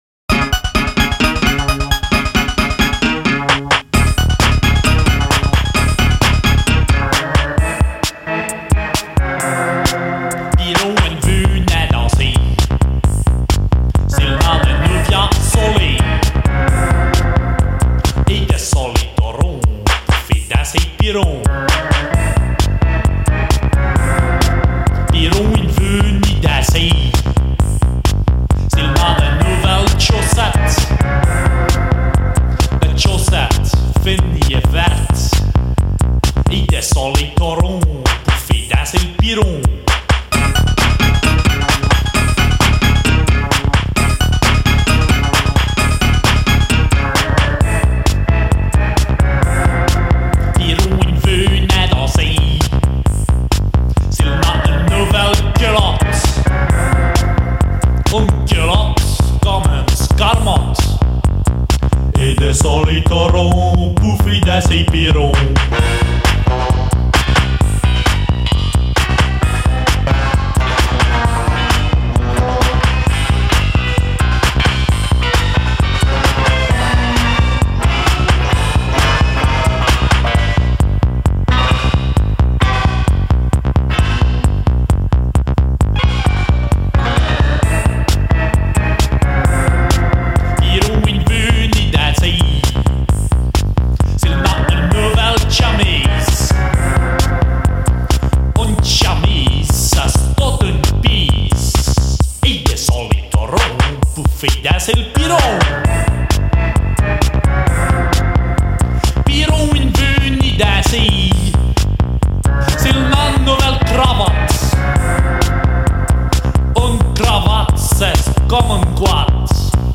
reprise d’une chanson traditionnelle
Musique électronico-avant-gardiste
texte en patois chanté